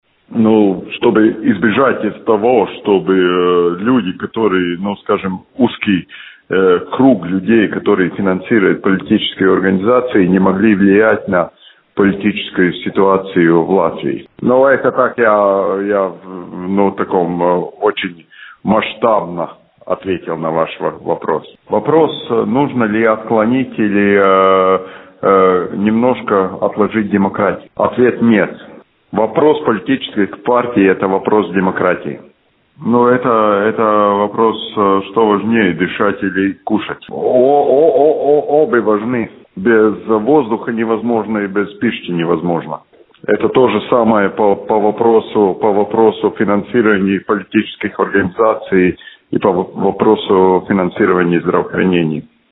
Увеличение государственного финансирования политических партий нельзя откладывать, поскольку этот вопрос напрямую касается демократии в Латвии. Об этом в интервью радио Baltkom заявил глава Бюджетно-финансовой комиссии Сейма, депутат от «Для развития/За!» Мартиньш Бондарс.